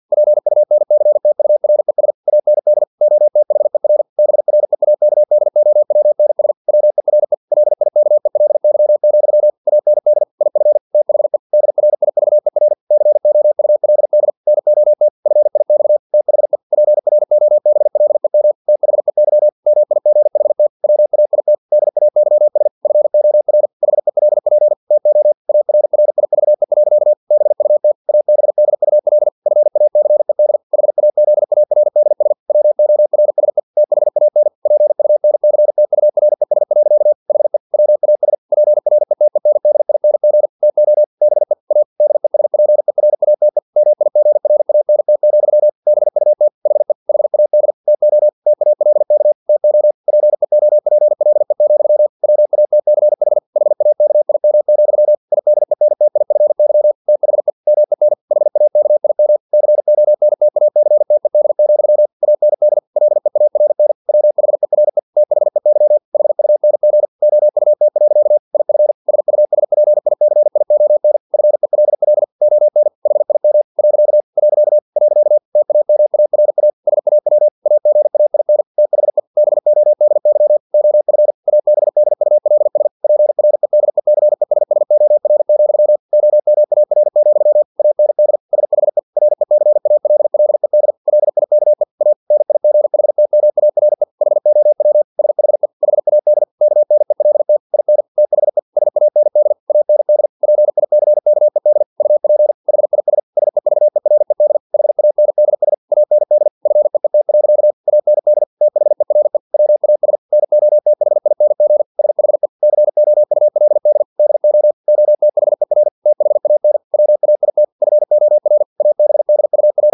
Never 49wpm | CW med Gnister
Never_0049wpm.mp3